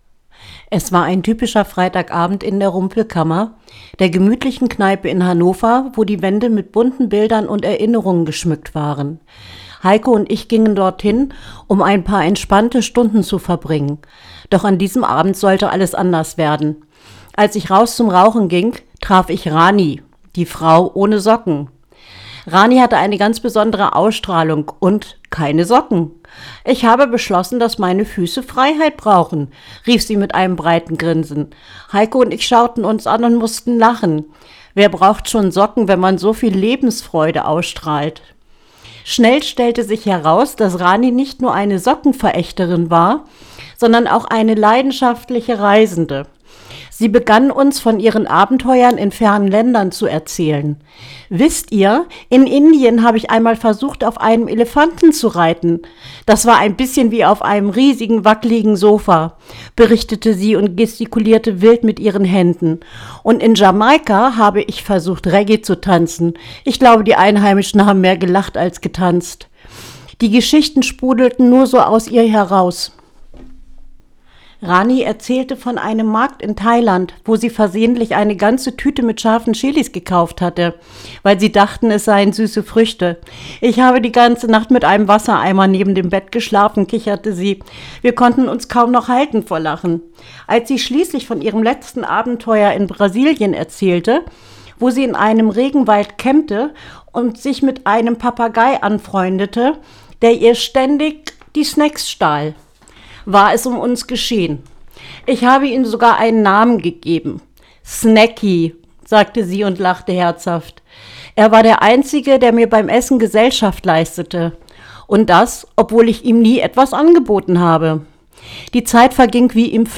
Professionelles Studio-Equipment garantiert erstklassige Soundqualität – klar, ausdrucksstark und bereit für dein Projekt.